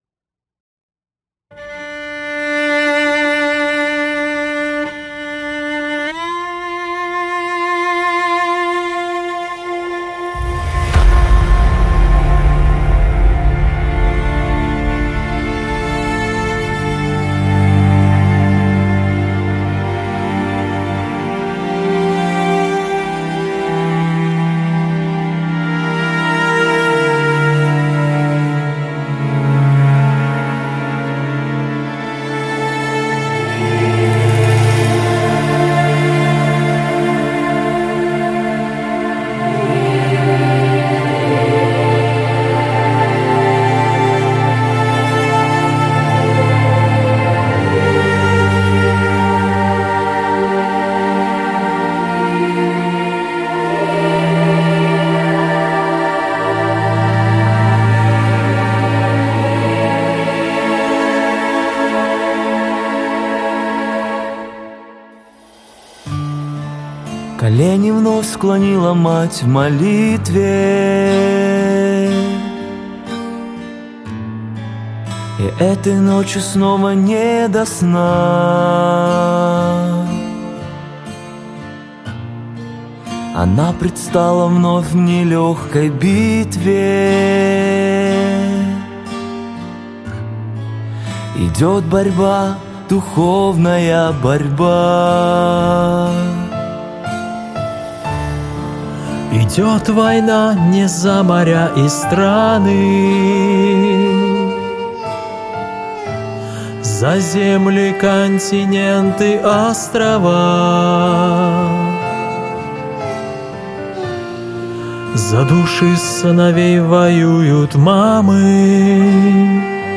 песня
262 просмотра 213 прослушиваний 24 скачивания BPM: 60